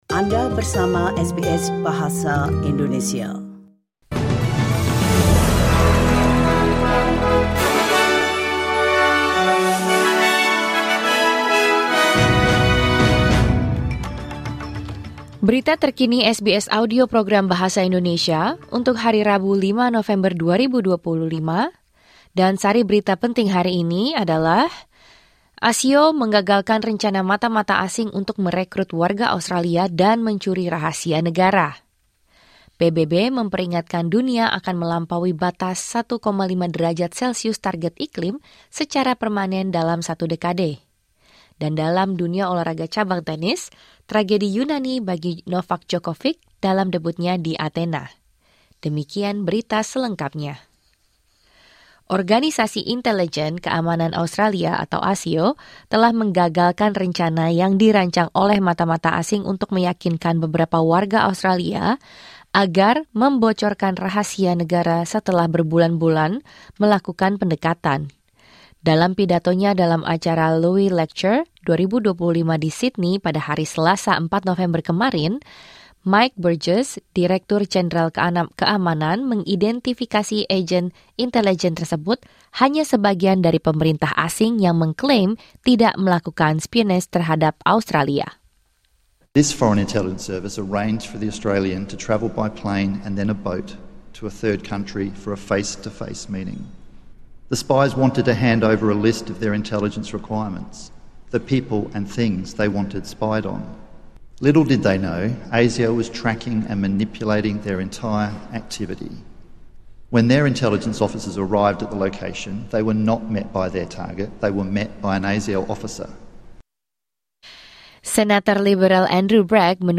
Berita Terkini SBS Audio Program Bahasa Indonesia – 5 November 2025